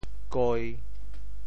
鸡（雞鷄） 部首拼音 部首 隹 总笔划 18 部外笔划 10 普通话 jī 潮州发音 潮州 goi1 文 潮阳 goi1 文 澄海 goi1 文 揭阳 goi1 文 饶平 goi1 文 汕头 goi1 文 中文解释 潮州 goi1 文 对应普通话: jī ①家禽之一，品种很多。